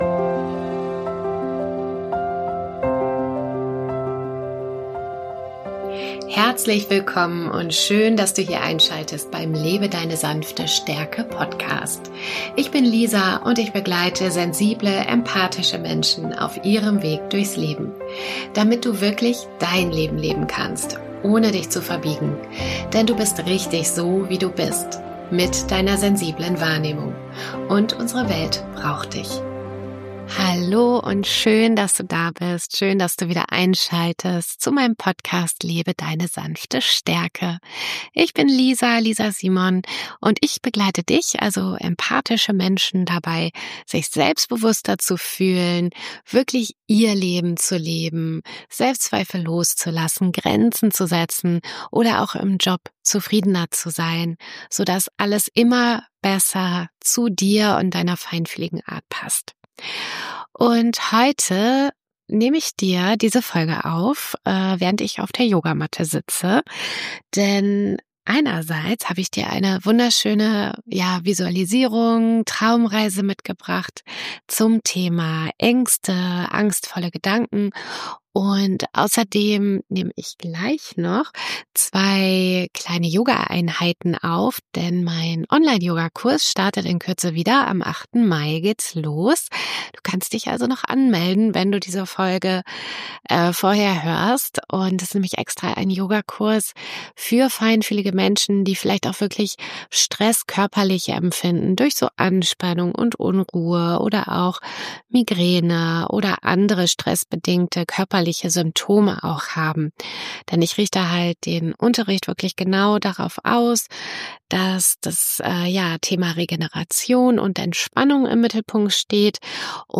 Eine geführte Traumreise für mehr Ruhe, Vertrauen und innere Stärke In dieser Folge nehme ich Dich mit auf eine wohltuende Visualisierungsreise. Du darfst es Dir gemütlich machen, tief durchatmen und loslassen, was Dich gerade beschwert.